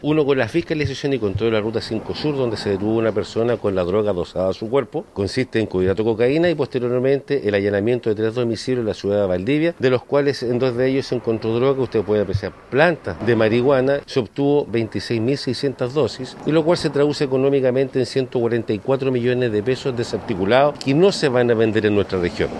El prefecto de Carabineros en Valdivia, coronel Juan González, precisó que entre ambos operativos “se obtuvo 26 mil 600 dosis, lo cual se traduce económicamente en $144 millones que se sacaron de circulación.